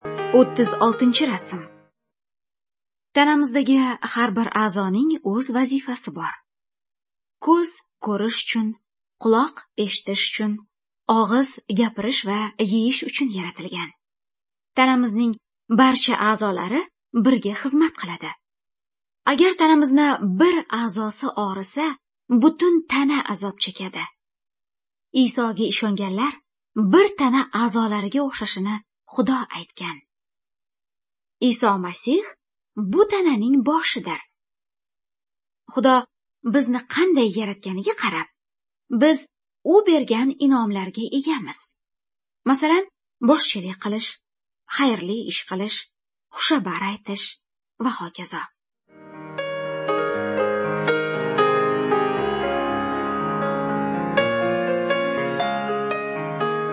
Good News (Female)